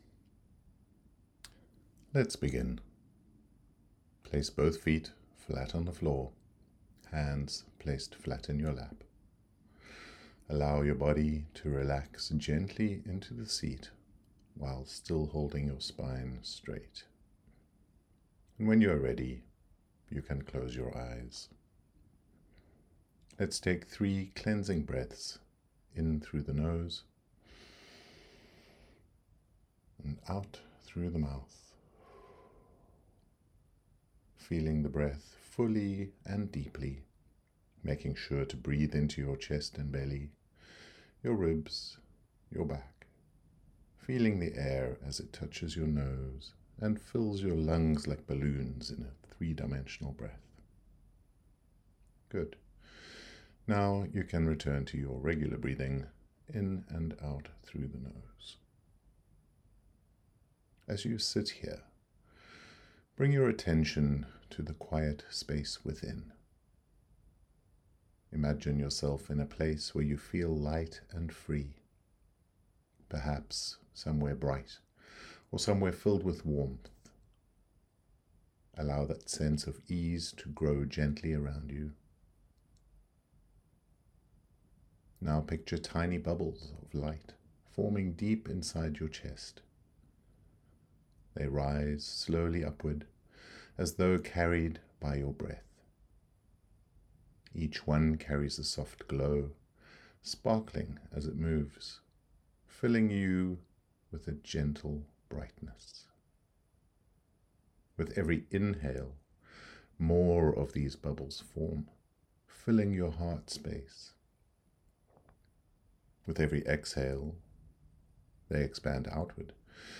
Bubbles + bauBles Meditation
WV07-meditation.mp3